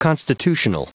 Prononciation du mot constitutional en anglais (fichier audio)
Prononciation du mot : constitutional